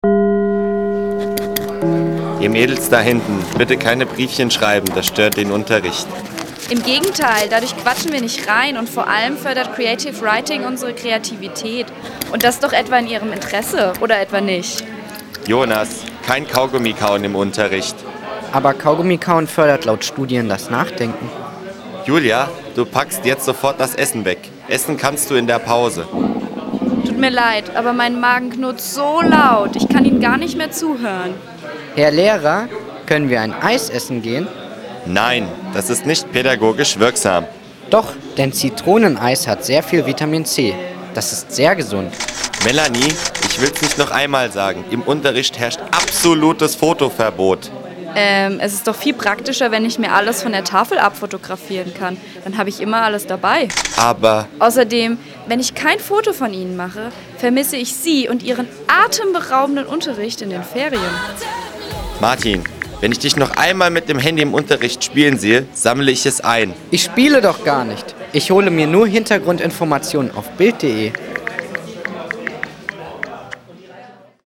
COMEDY_SFX_LEHRER_ÜBERREDEN.mp3